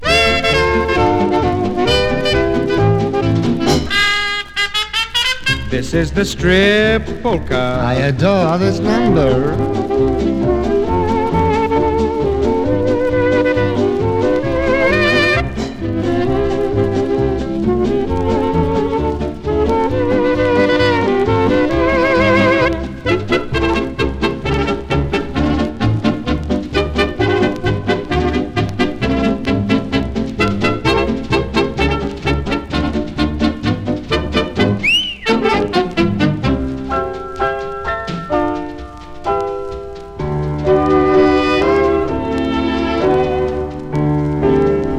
Jazz, Pop, Vocal, Big Band　USA　12inchレコード　33rpm　Stereo